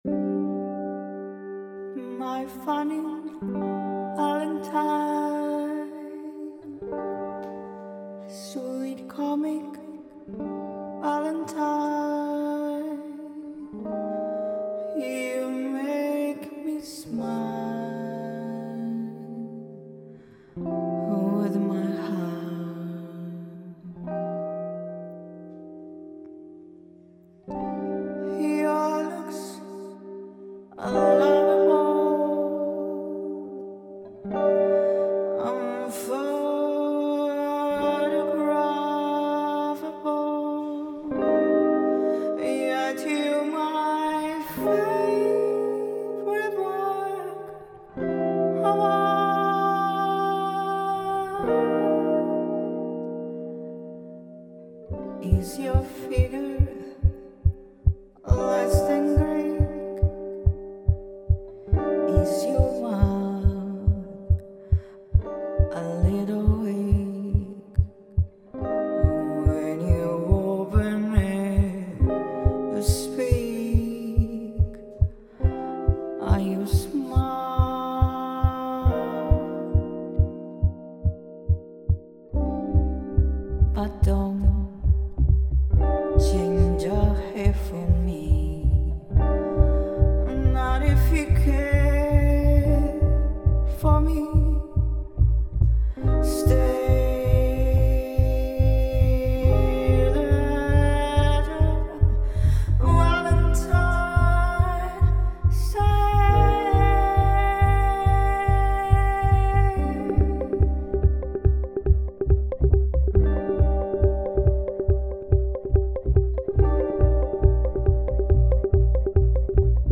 arrangiamento per voce, chitarra e loop station